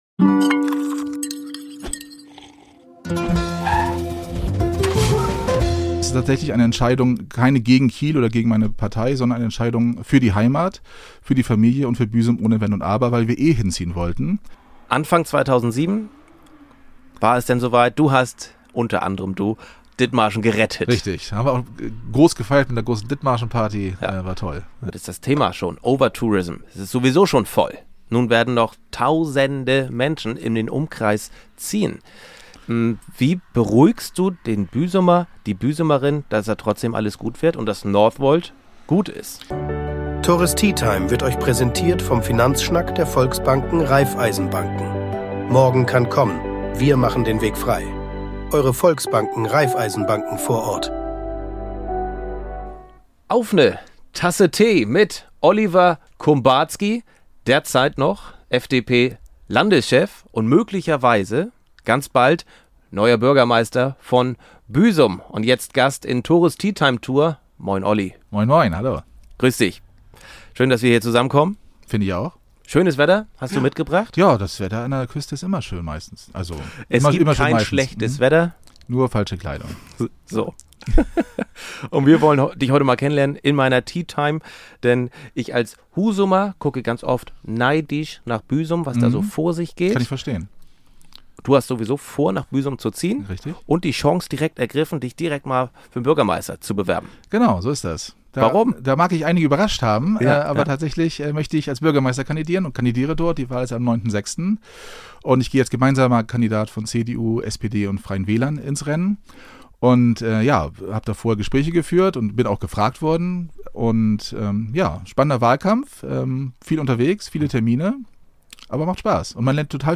Hört mal rein in dieses interessante Gespräch mit dem Vollblut-Dithmarscher, der schon 2006 landesweit für Aufsehen sorgte, als er Dithmarschen "gerettet" hat.